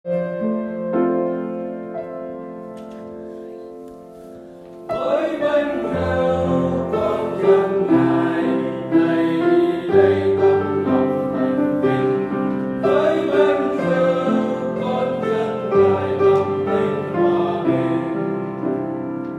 Bè Nam
TamTinhHoaBinh-(BeNam).m4a